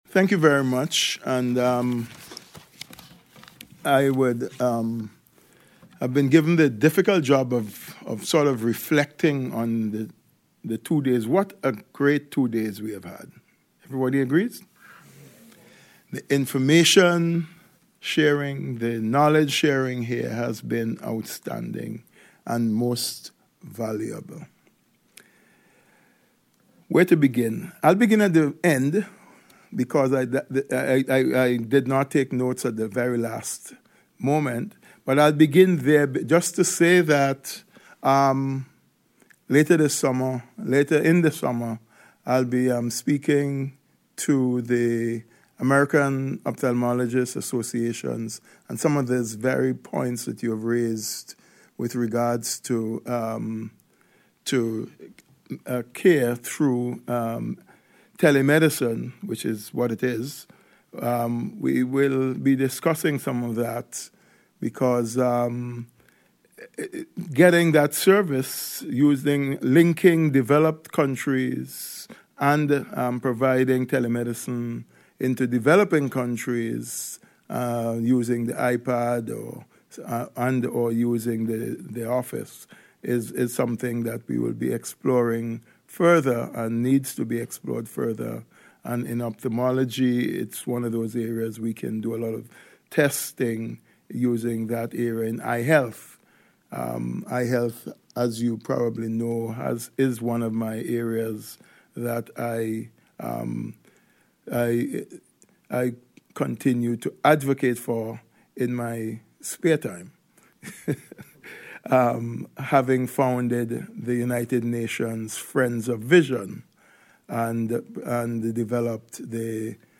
As RNIB Scotland's Inclusive Design for Sustainability Conference came to an end in Glasgow it was time for closing reflections.
Returning to the stage for his final thoughts on the event and its importance was Dr Aubrey Webson, Permanent Representative of Antigua and Barbuda to the United Nations.